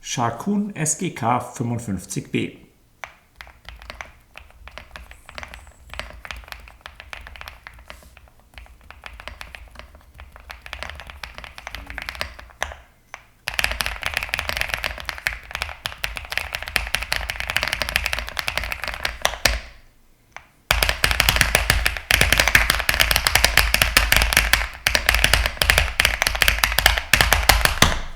Ihre Materialwahl gewährleistet ploppende Rückmeldungen bei Betätigung, die durch die Bühne, das Chassis der Tastatur, lediglich moderiert werden kann.
Die Silikonmatte bringt zwar eine grundsätzliche Ruhe in die Klangkulisse, die Abstimmung produziert aber einen „thocky“-Sound mit ploppenden Geräuschen schon beim Antippen der Taster.
Sharkoon produziert einen satteren, volleren Thock, der trockener klingt.
Der Preis des ploppenden Thocks: Es entsteht ein Klangteppich, bei dem sich einzelne Anschläge nur schwer individuell wahrnehmen lassen.